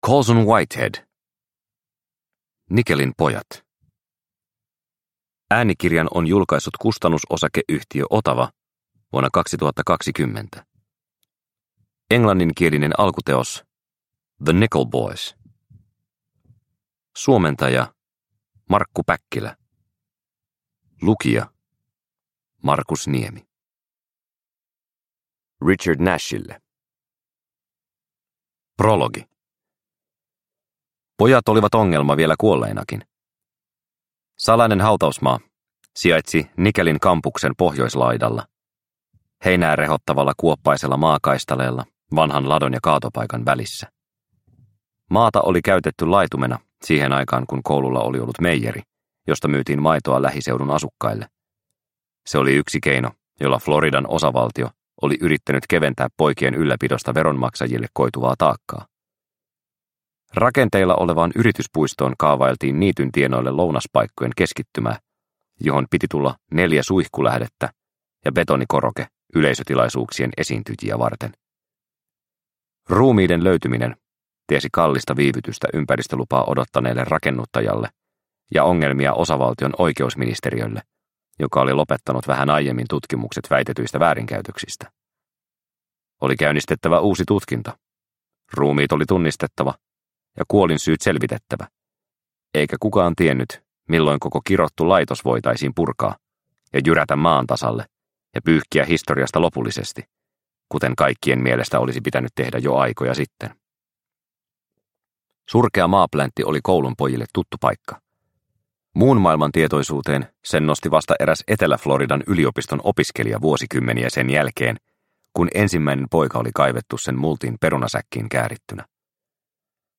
Nickelin pojat – Ljudbok – Laddas ner